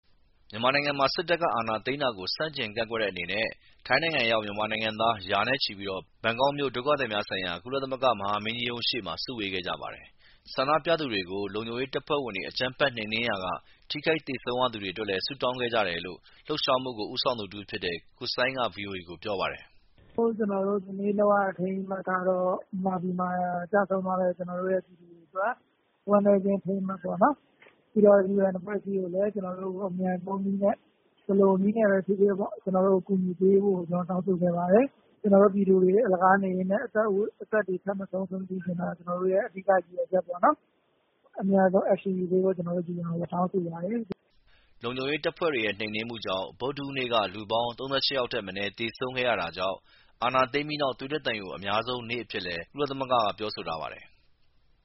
မတ်လ ၄ရက်နေ့ညက UNHCR ရုံးရှေ့မှာမြန်မာအလုပ်သမားတွေ ဆုတောင်းပွဲနဲ့ဆန္ဒထုတ်ဖော်ပွဲပြုလုပ်ခဲ့ပုံ